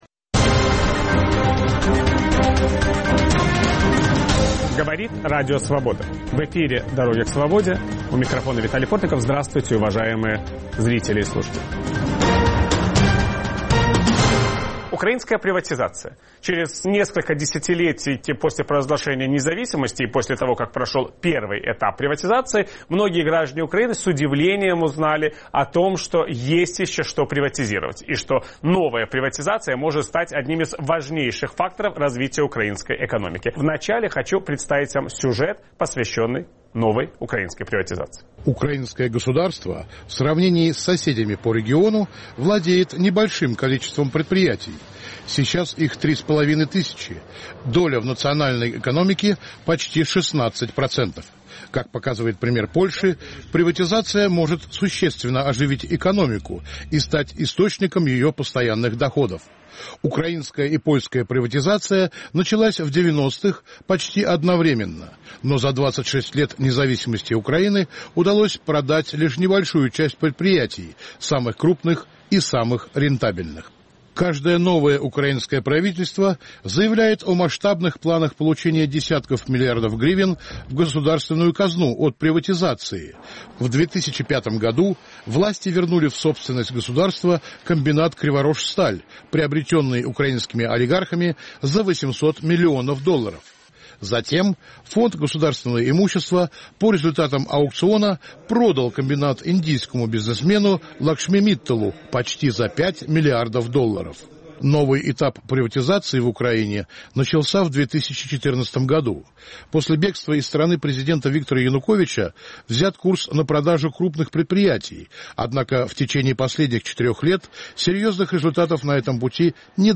Почему спустя 26 лет после провозглашения независимости приватизация государственных компаний в Украине по-прежнему акутальна? Собеседник Виталия Портникова